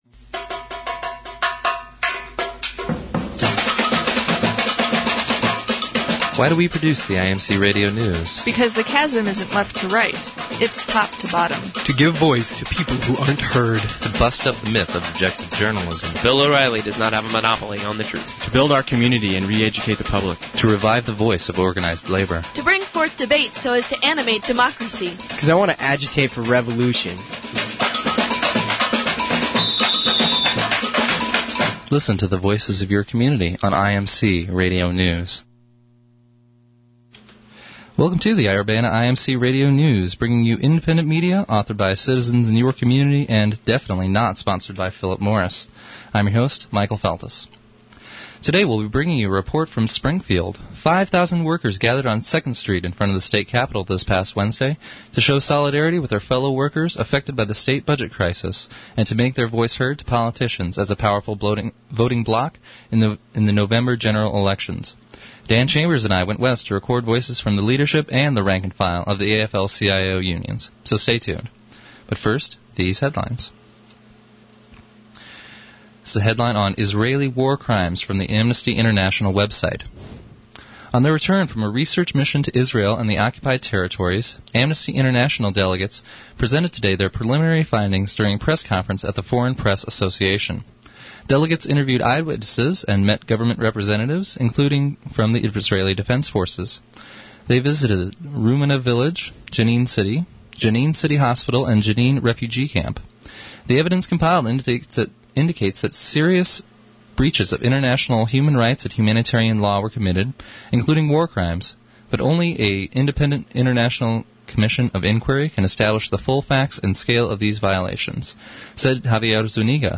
IMC Radio News
from the 24 Apr 2002 AFL-CIO rally in Springfield